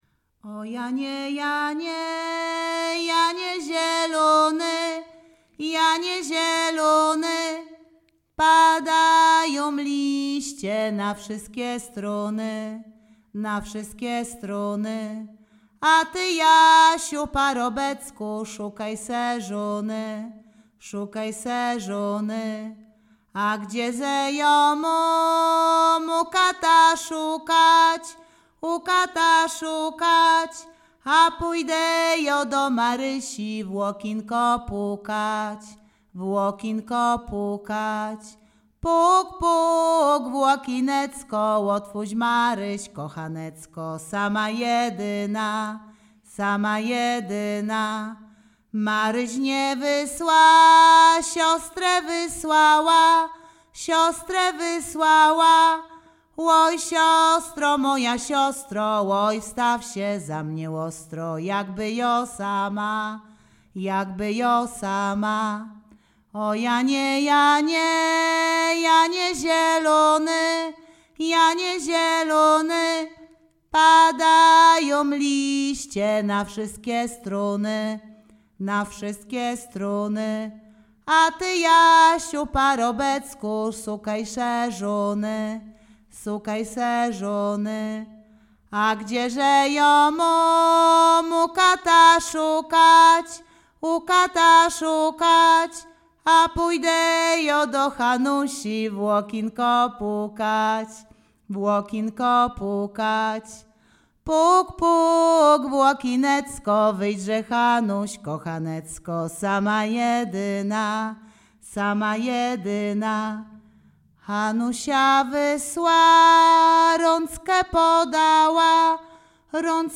Sieradzkie
kupalskie świętojańskie sobótkowe liryczne miłosne